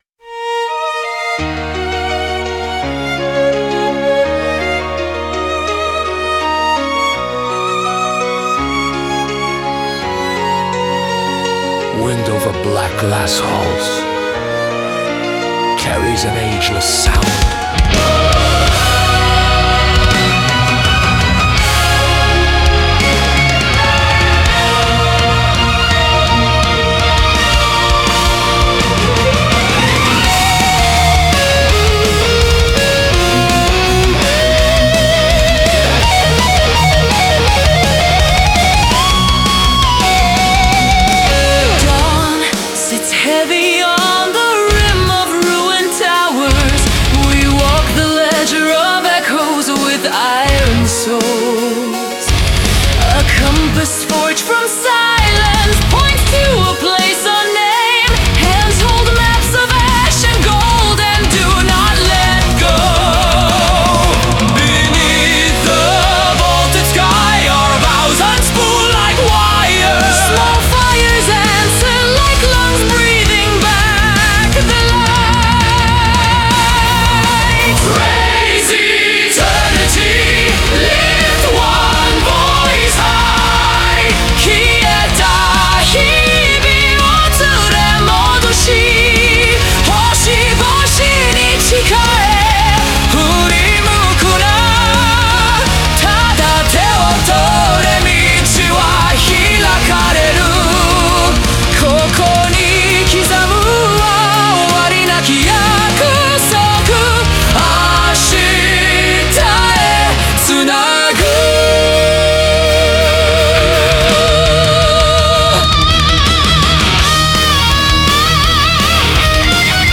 Symphonic Metal